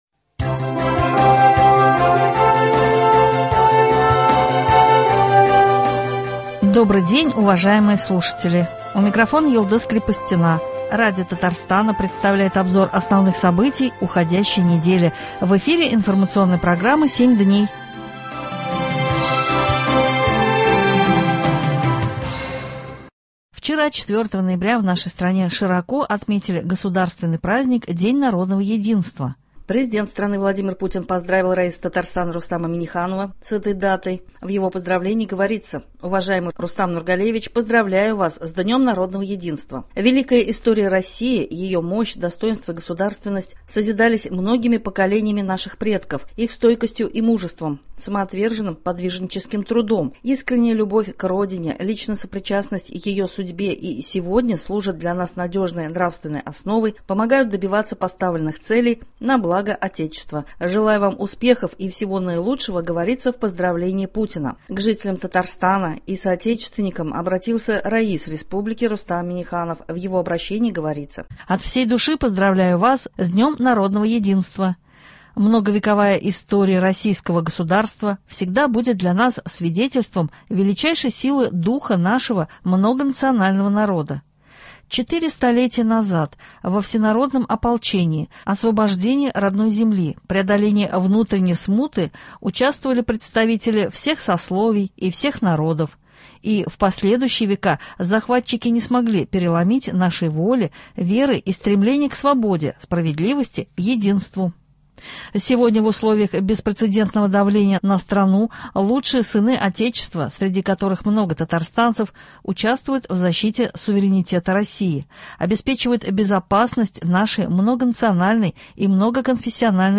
Обзор событий. 4 ноября в нашей стране широко отметили государственный праздник – День народного единства.